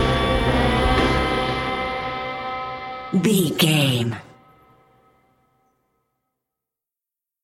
Thriller
Aeolian/Minor
synthesiser
percussion
tension
ominous
dark
suspense
haunting
creepy